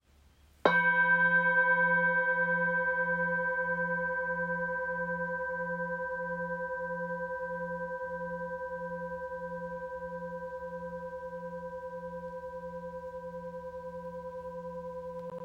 The rippled texture enhances the bowl’s resonance, producing rich, layered tones that are soothing, immersive, and full of harmonic depth.
Lightweight and easy to hold, it responds beautifully to gentle strikes or rim-rubbing techniques, creating a calm, grounding sound that fills the space.
Heart-bowl-stick.m4a